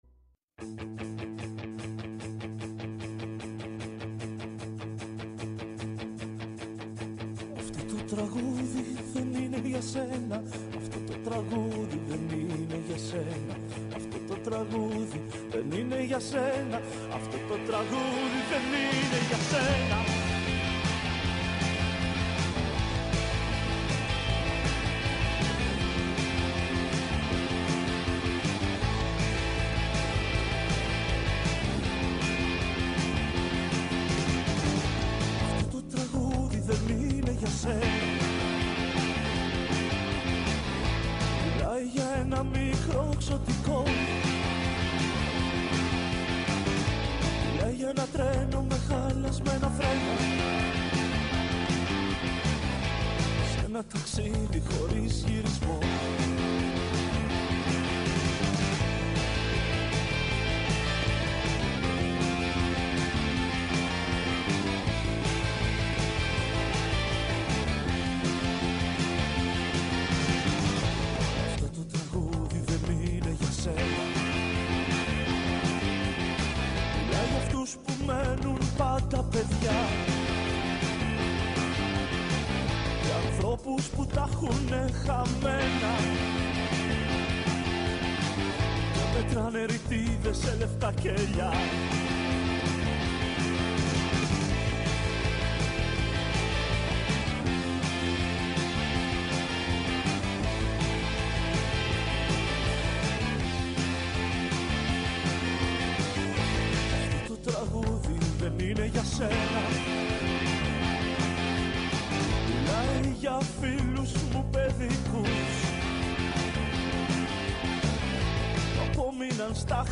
ΔΕΥΤΕΡΟ ΠΡΟΓΡΑΜΜΑ Αφιερώματα Μουσική